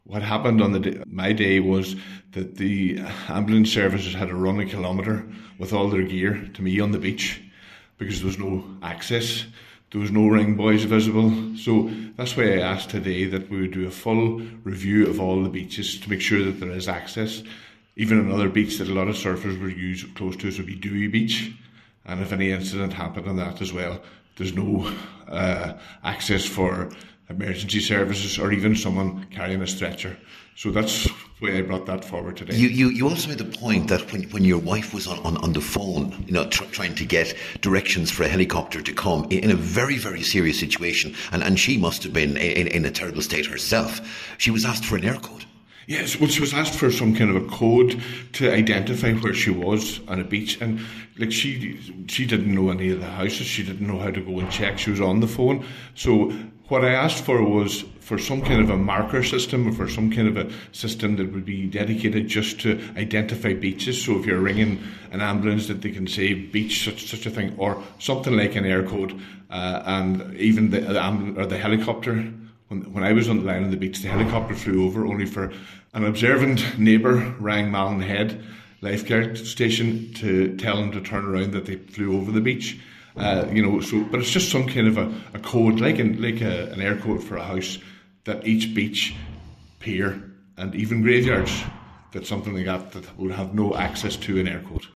Cllr Carr told a Glenties MD meeting that the ambulance which brought had to park a kilometre away when he had his incident: